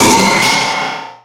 Cri de Méga-Scarabrute dans Pokémon X et Y.
Cri_0127_Méga_XY.ogg